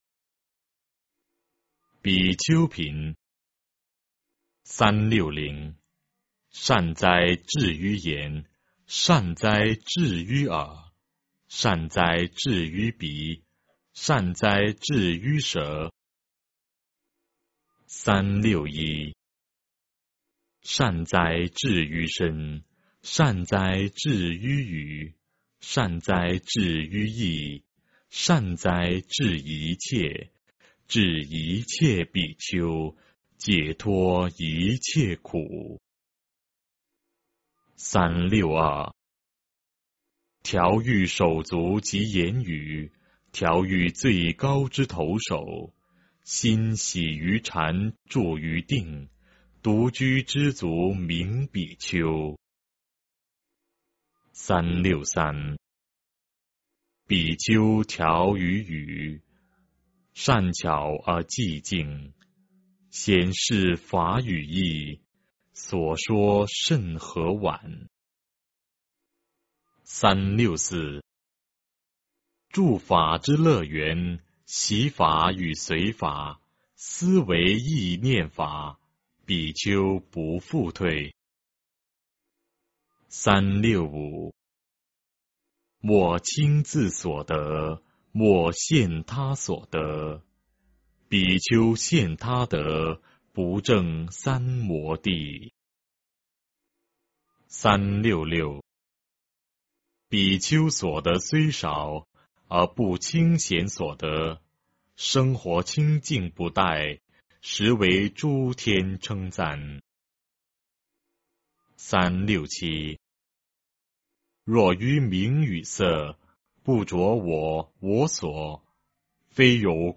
法句经-比丘品（念诵）